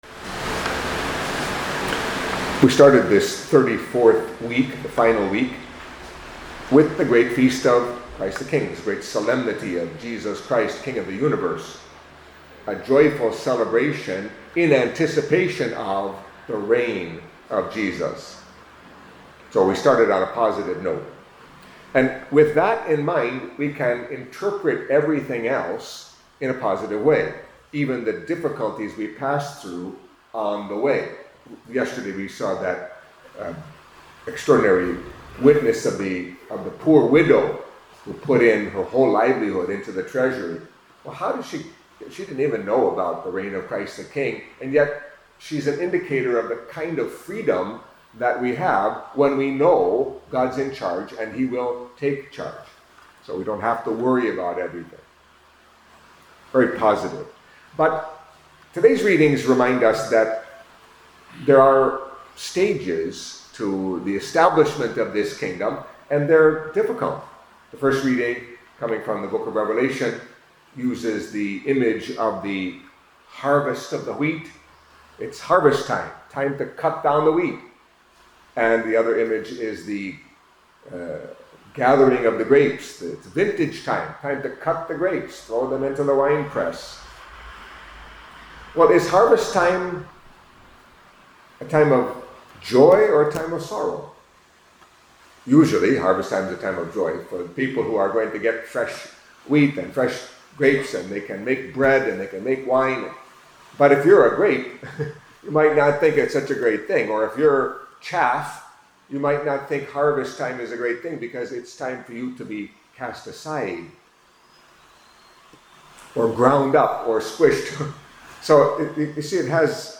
Catholic Mass homily for Tuesday of the Thirty-Fourth Week in Ordinary Time